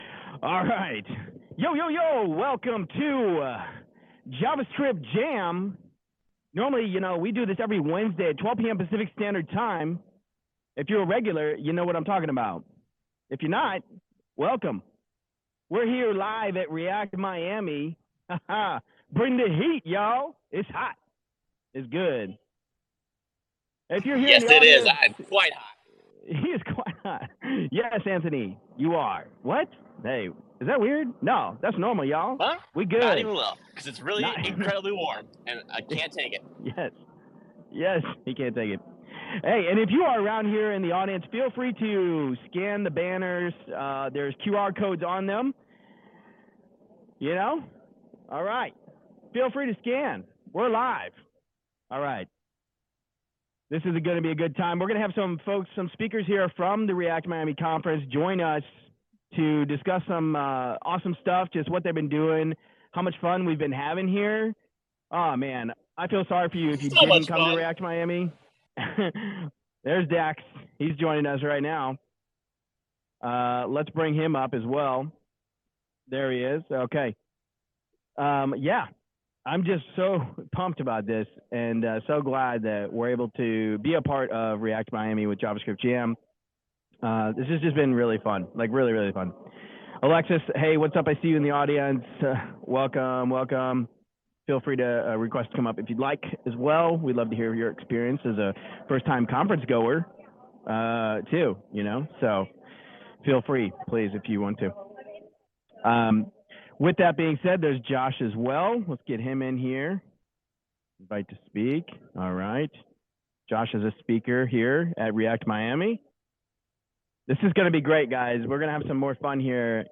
Live at React Miami Recap with Event Speakers